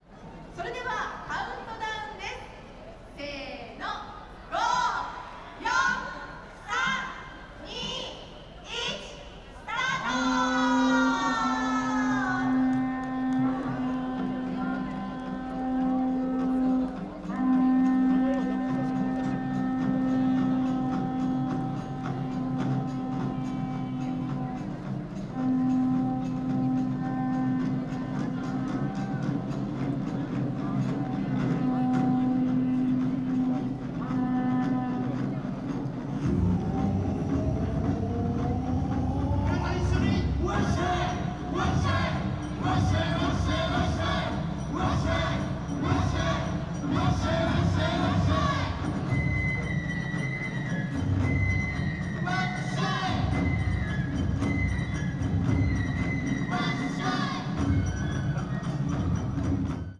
Fukushima Soundscape: Machi-naka Park